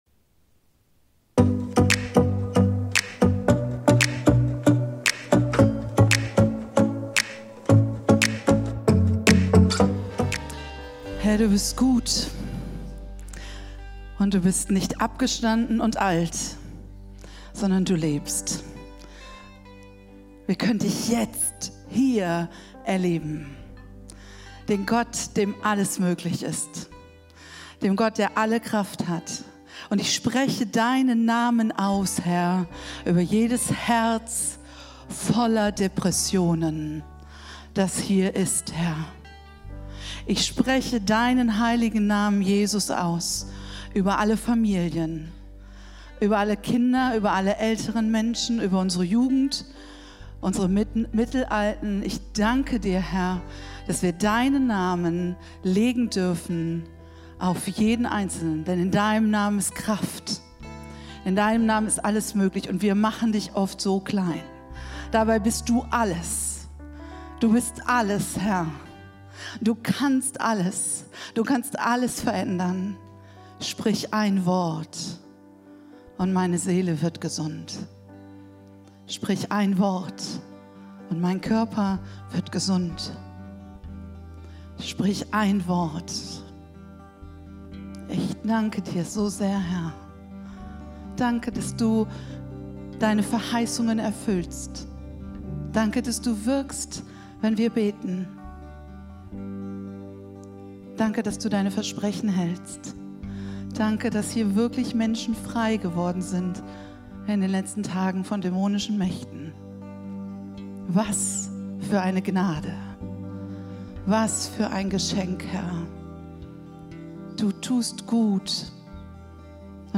Live-Gottesdienst aus der Life Kirche Langenfeld.
Kategorie: Sonntaggottesdienst Predigtserie: Freunde fürs Leben - Wie gute Beziehungen gelingen